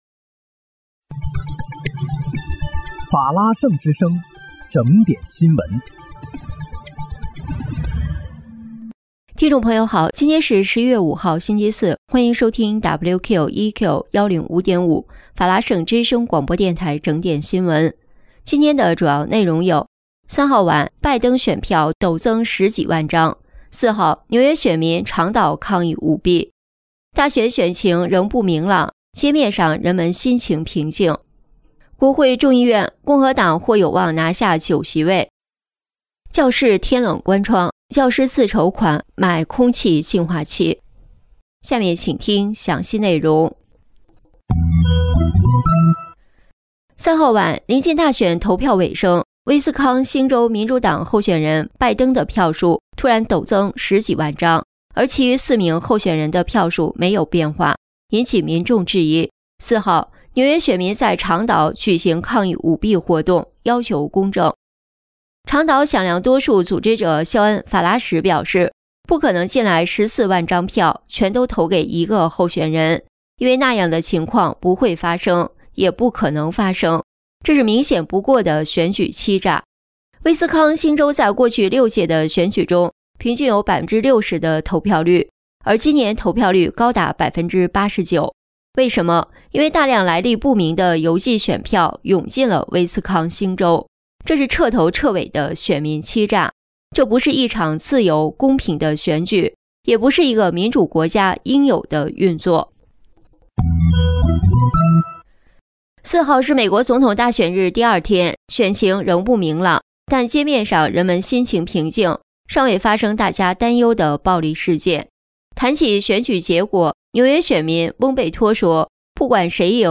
11月5日（星期四）纽约整点新闻